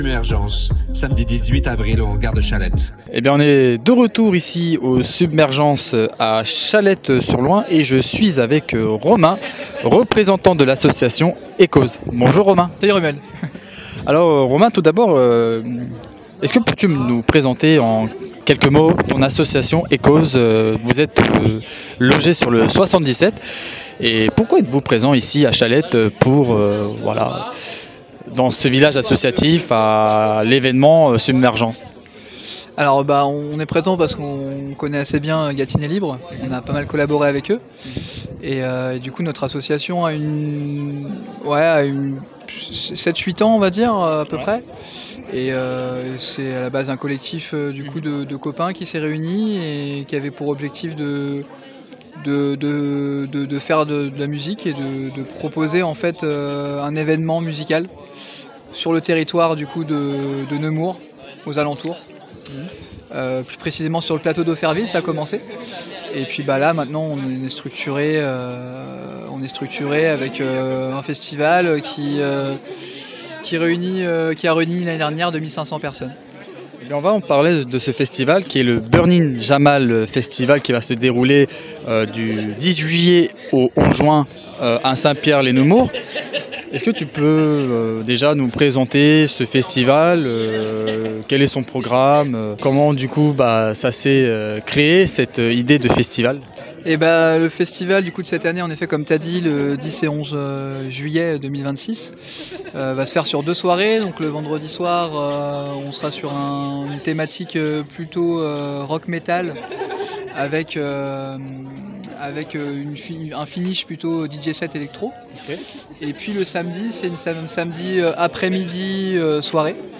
Dans cet épisode, on vous emmène au cœur du village associatif de Submergence, un événement porté par Gâtinais Libre au Hangar de Châlette-sur-Loing.